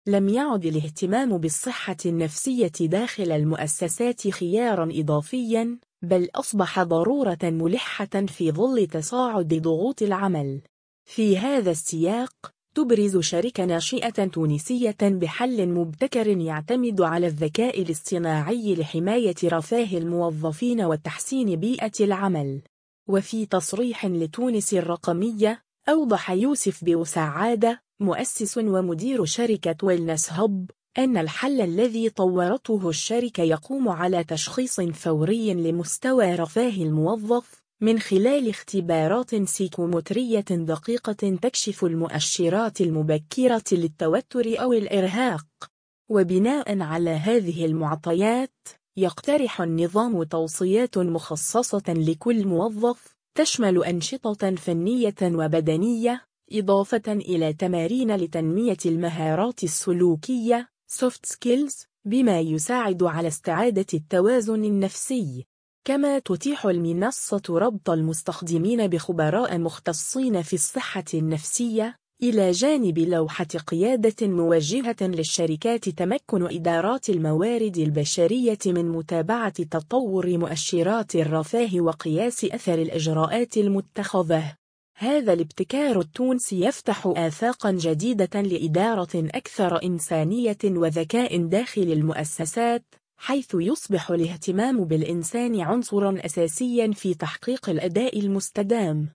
وفي تصريح لتونس الرقمية